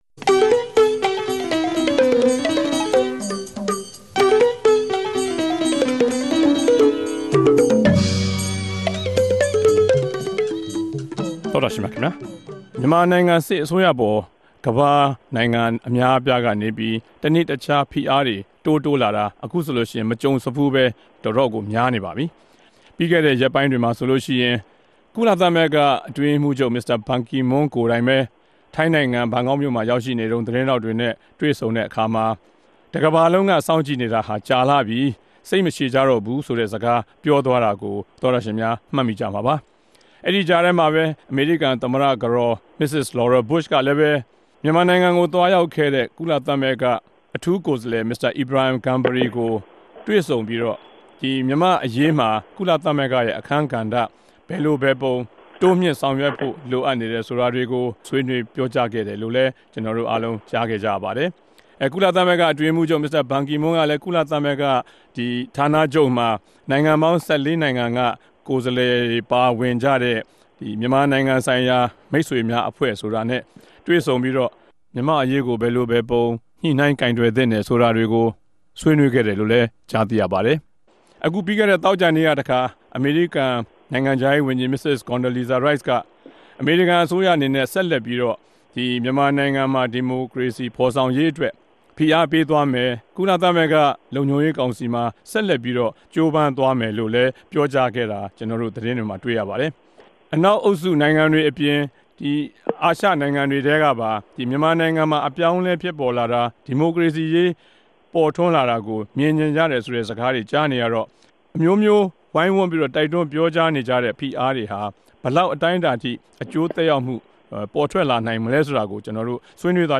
တပတ်အတြင်း သတင်းသုံးသပ်ခဵက် စကားဝိုင်း